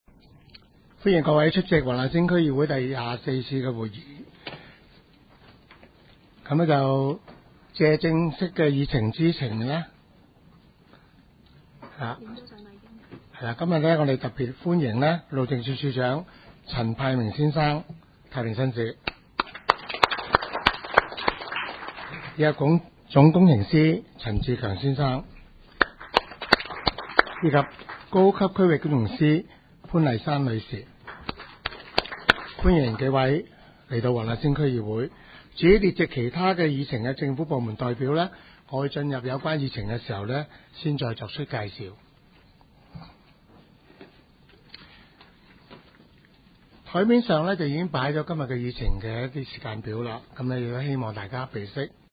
区议会大会的录音记录
黄大仙区议会会议室
主席致辞